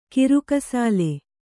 ♪ kirukasāle